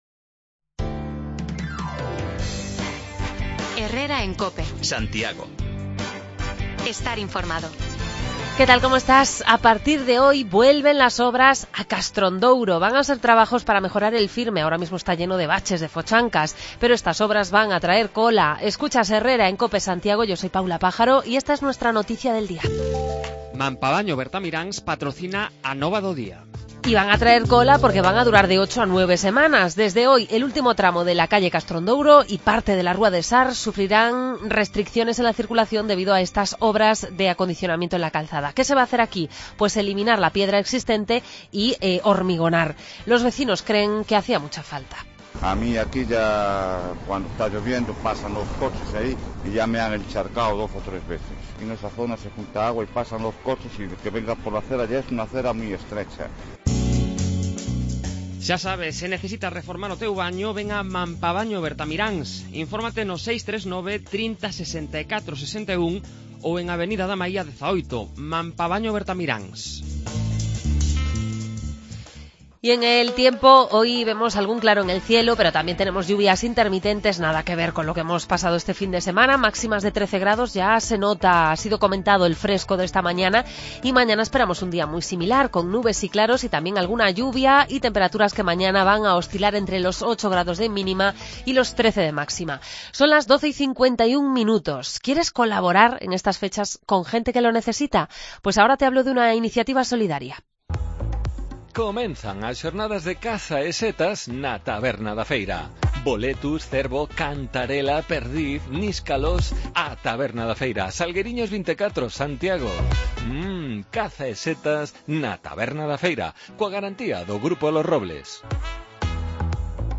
Hoy arrancan las obras de rebacheo en el último tramo de la rúa Castrón Douro y en Sar, así que nos hemos acercado hasta allí para recoger testimonios entre vecinos y comerciantes. En la radio nos han visitado representantes de varias confradías de Semana Santa que ponen en marcha un año más una campaña de recogida de alimentos no perecederos, mantas, ropa, juguetes... para donar a familias que se encuentran en situación económica más comprometida.